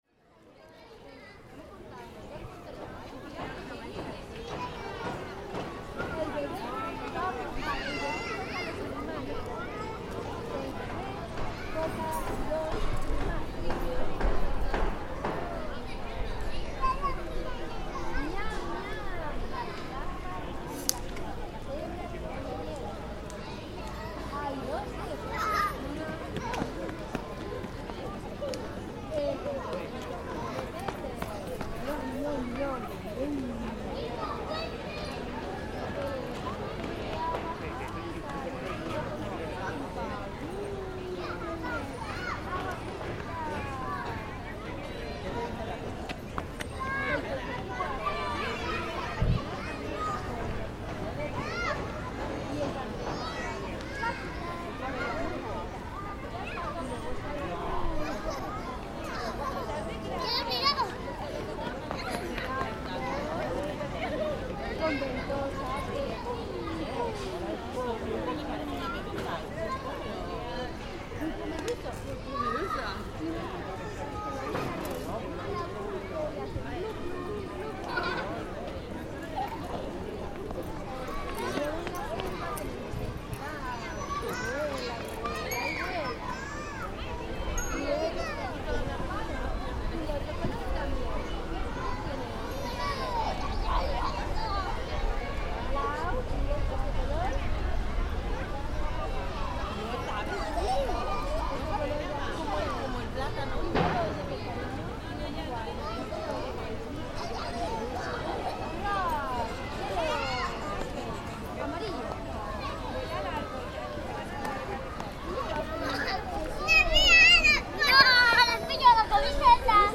Children playing after school in a plaça in Palma, Mallorca as groups of parents look on and chat amongst themselves. The soundscape of a bustling city makes itself known on all sides around us.